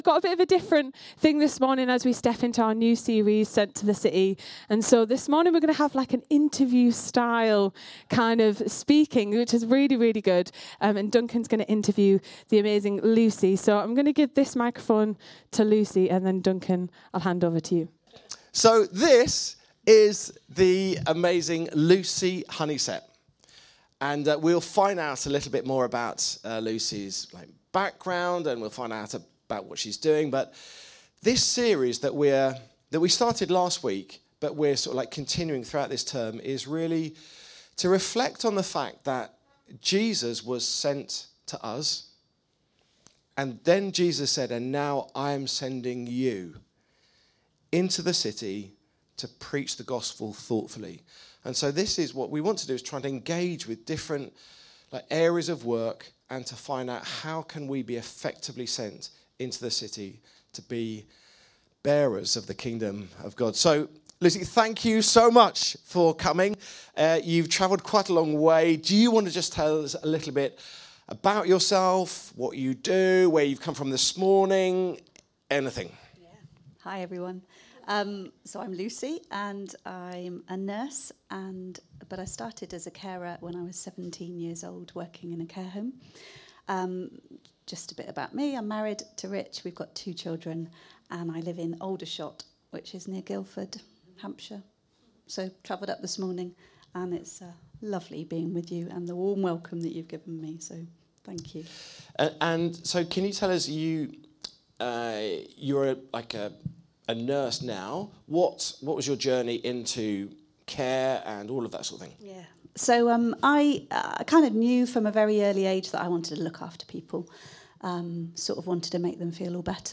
Download Sent To The Care Industry | Sermons at Trinity Church